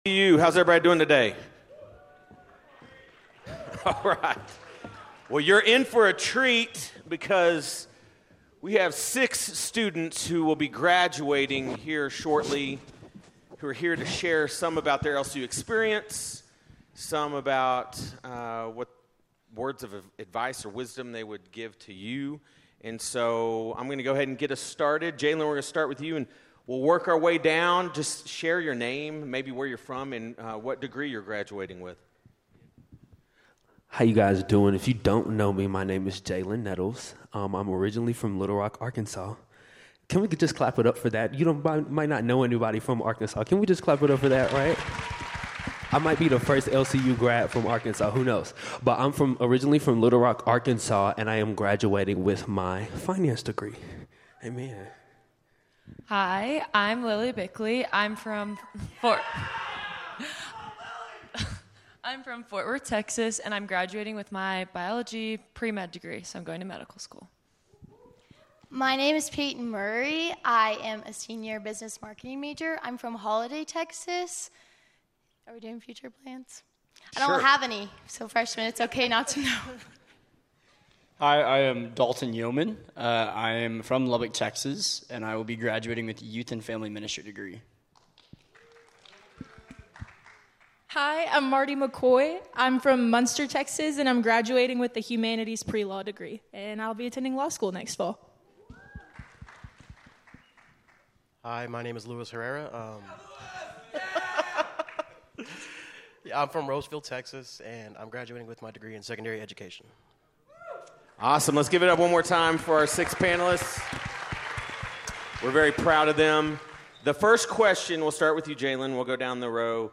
Graduating seniors reflect on their college journey, sharing honest advice about faith, identity, growth, and embracing change before stepping into what’s next.